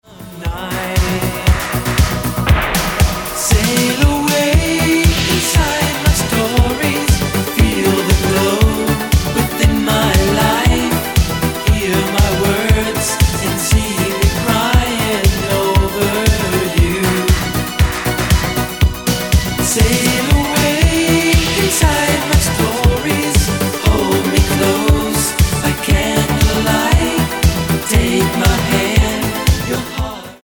The Disco Star of the 80s!